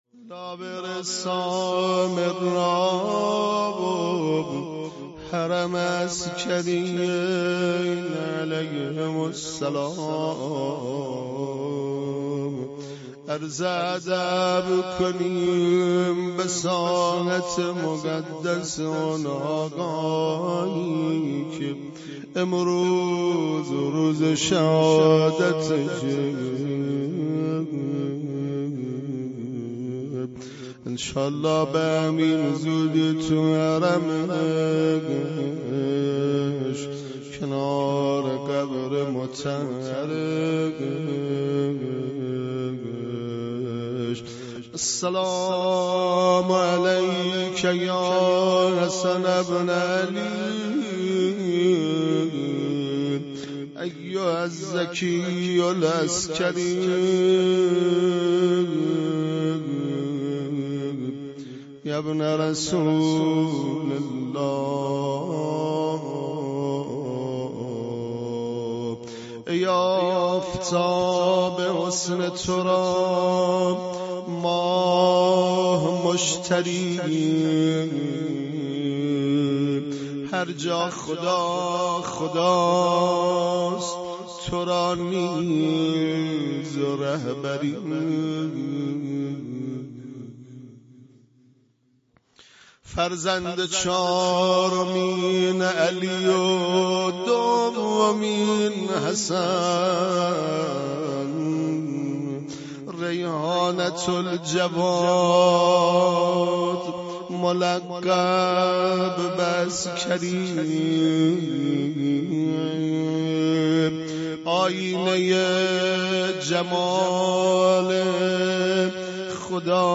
روضه شهادت امام حسن عسکری